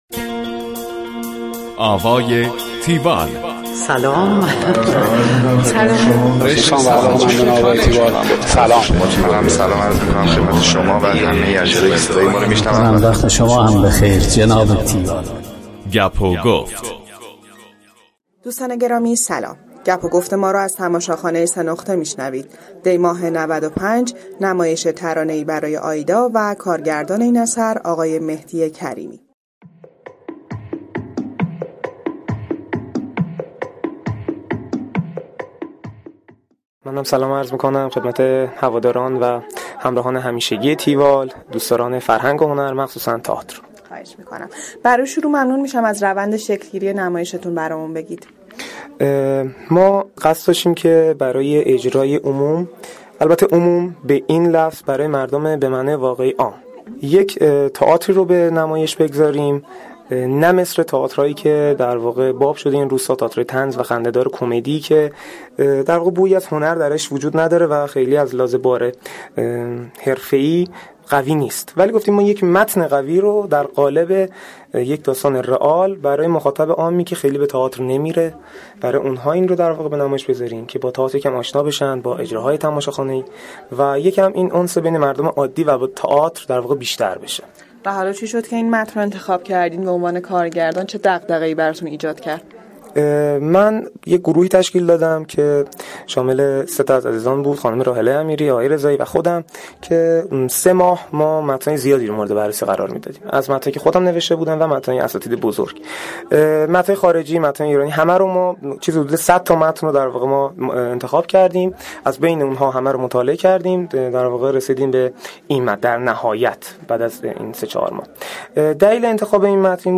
گفتگو کننده: